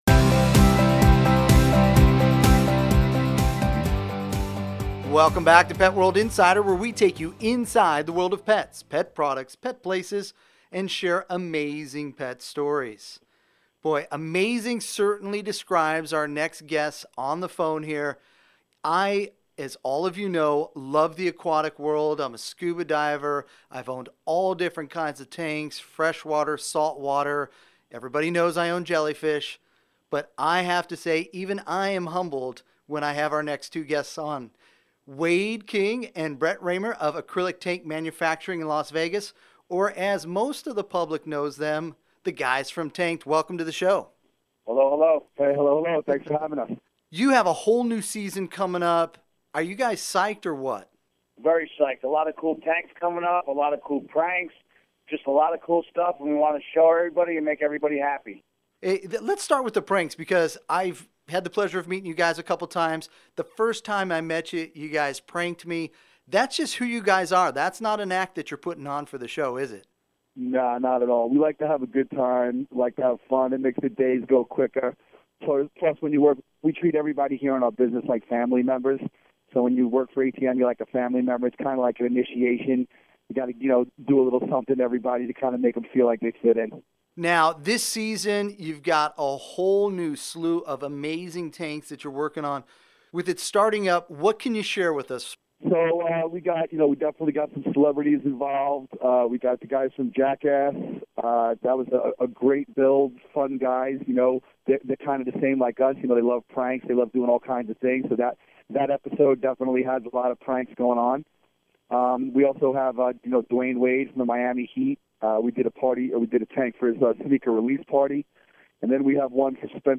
In this Pet World Radio Segment we sit down with Brett Raymer & Wayde King from Animal Planet’s Tanked